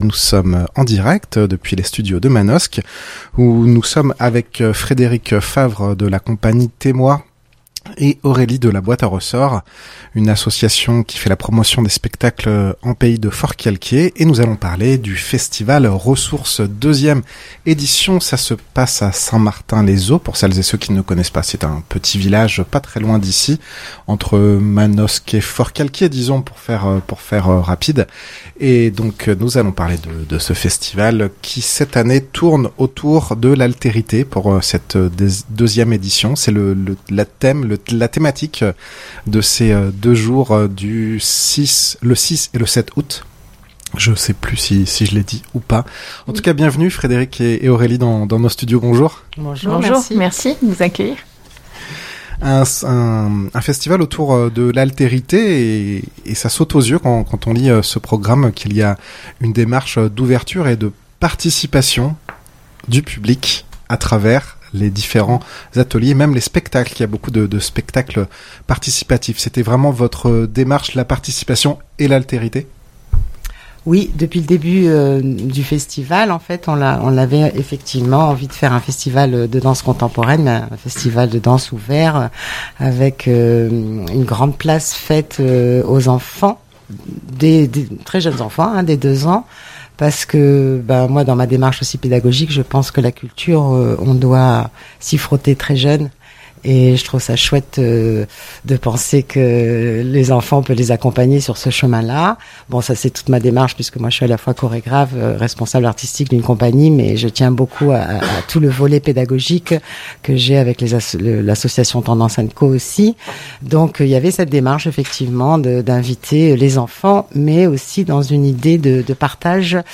en direct depuis les studios à Manosque.
Interview